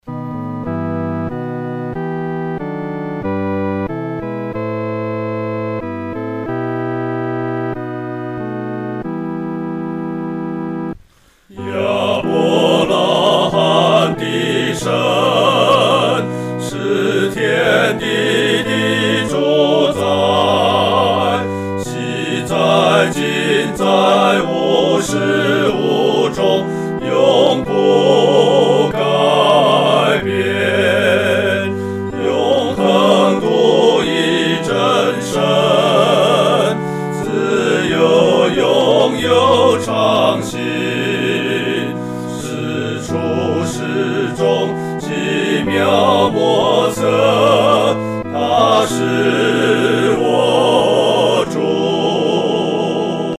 合唱（四声部）